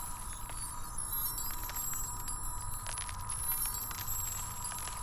Magic_Glow_01.wav